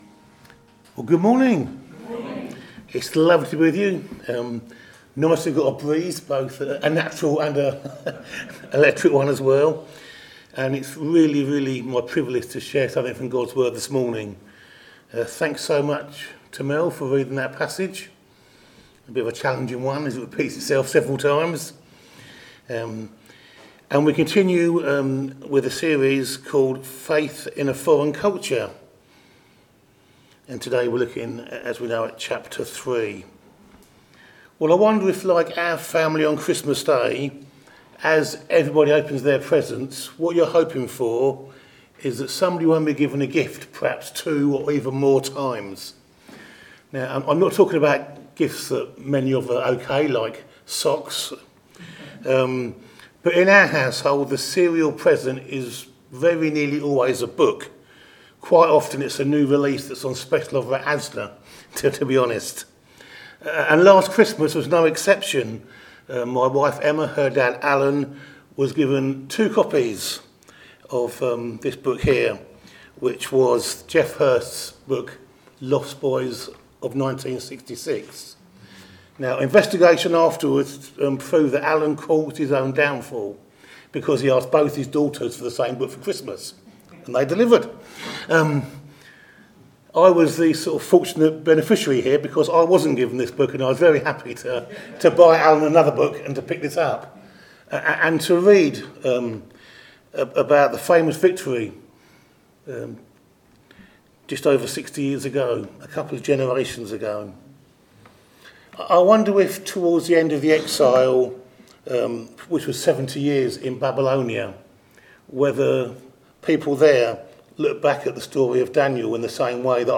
Media for a.m. Service on Sun 22nd Jun 2025 10:30
Passage: Daniel 3 Series: Daniel - Faith in a foreign culture Theme: Sermon